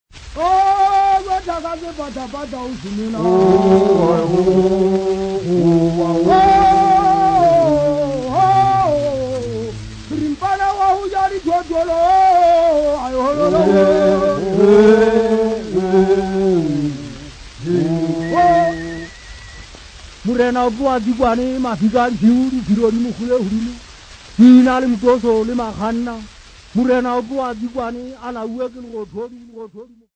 Folk Music
Field recordings
Africa South Africa Johannesburg f-sa
sound recording-musical
Indigenous music